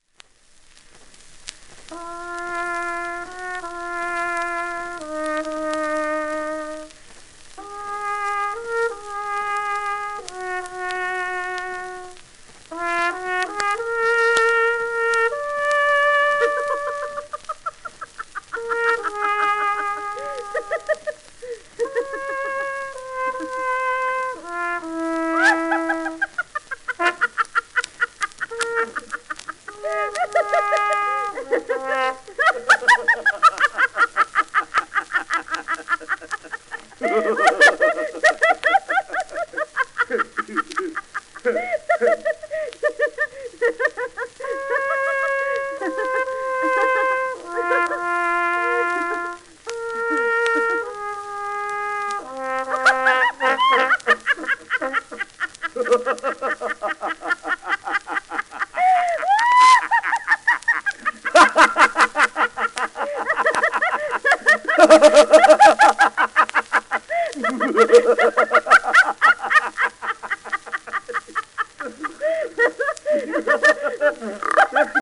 盤質B+/B *薄いエッジクラック(紙芯の影響による経年のもの音影響少)、面スレ、キズ